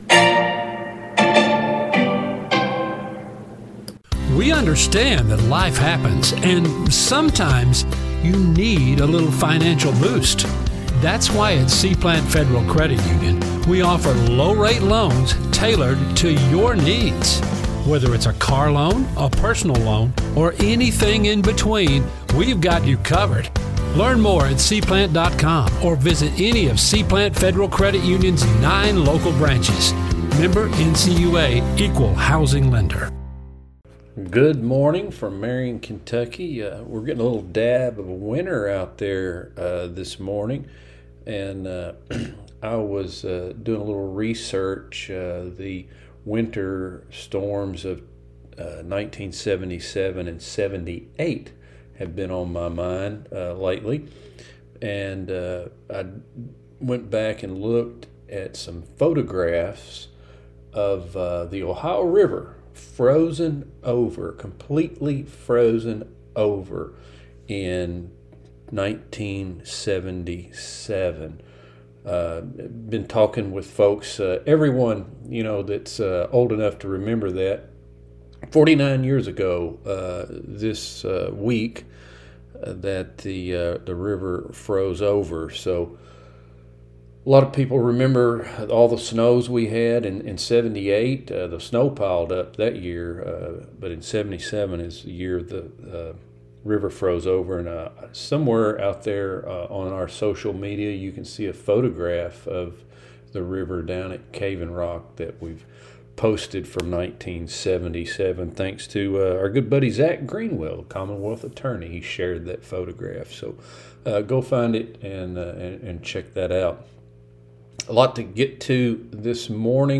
STATE FARM | THURSDAY NEWScast